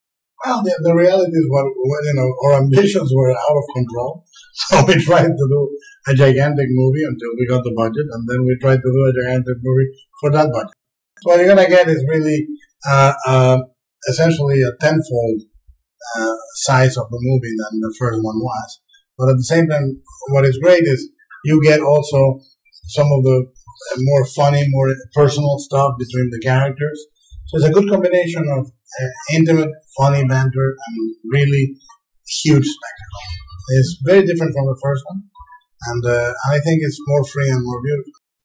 Interview with Guillermo del Toro